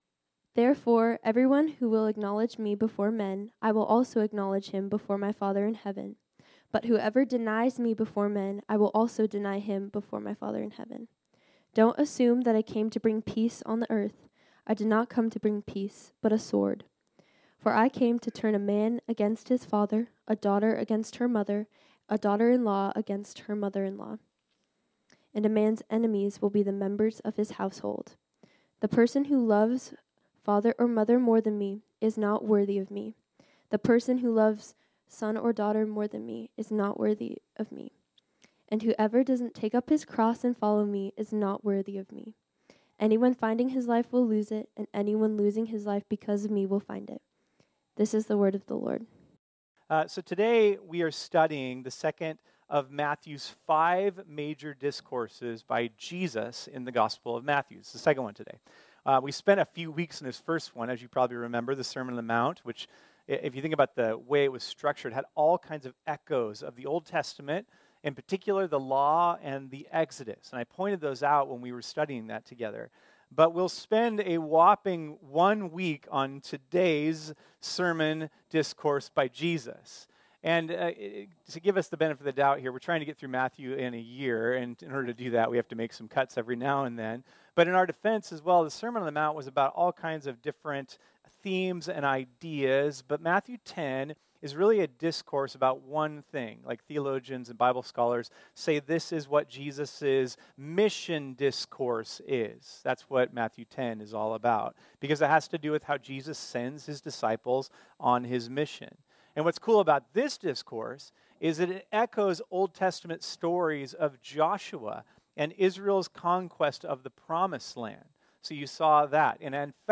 This sermon was originally preached on Sunday, May 5, 2024.